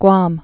(gwäm)